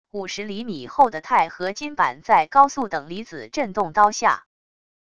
50厘米厚的钛合金板在高速等离子振动刀下wav音频